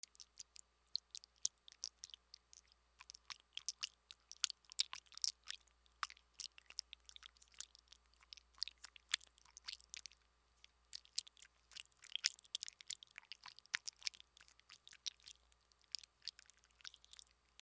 004 水音 02
クチュ音